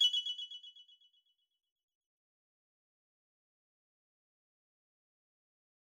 back_style_4_echo_005.wav